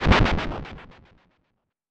grass cutter.wav